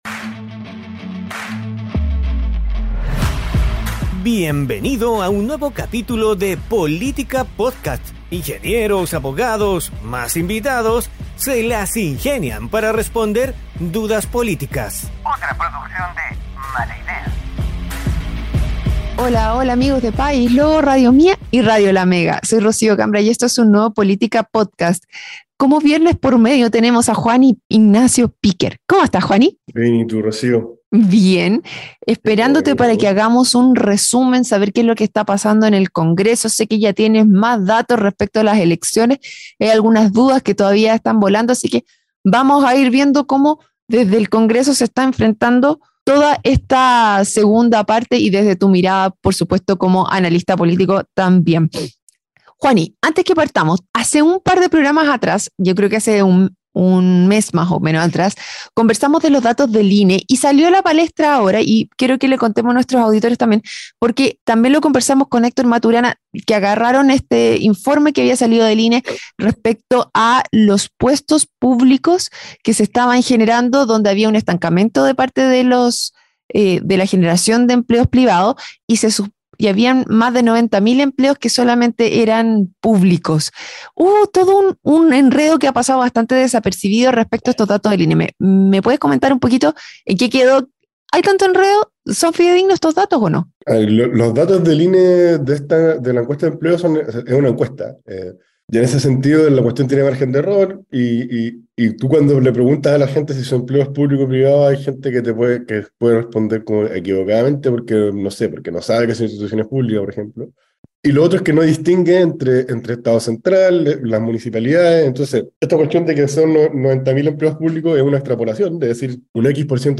programa donde junto a panelistas estables e invitados tratan de responder dudas políticas.